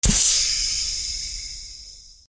水ロケットの発射音＿強 B1-02 (+AttackAction)
/ G｜音を出すもの / Ｇ-15 おもちゃ